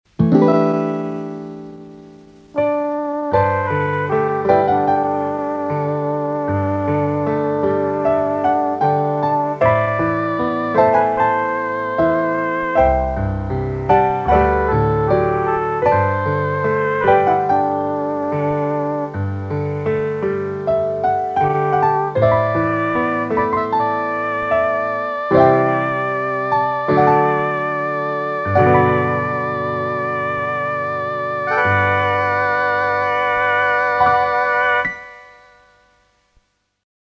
ダウンロード(null) ←これがpss 790のﾅｲﾛﾝ弦ｷﾞﾀｰとﾋﾟｱﾉ＆フリューゲルホーンを弾いた音です